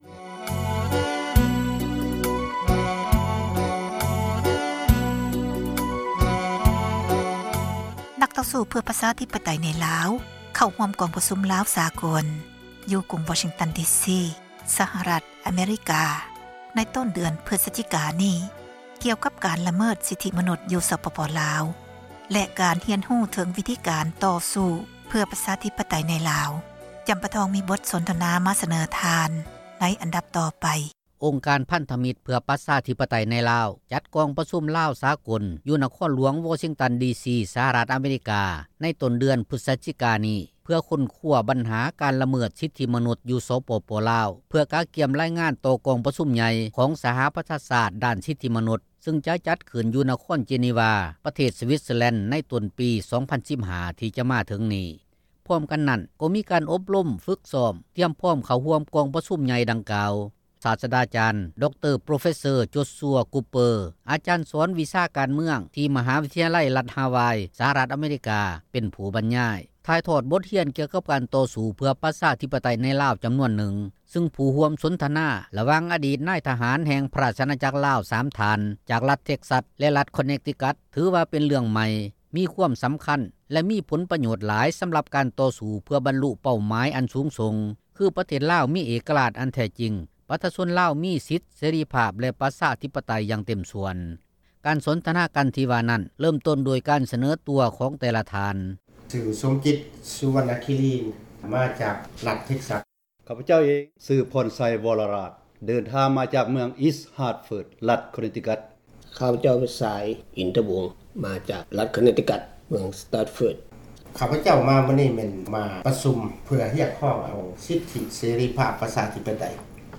ສໍາພາດນັກຕໍ່ສູ້ເພື່ອສິດທິ ຊາວລາວ ຢູ່ລາວ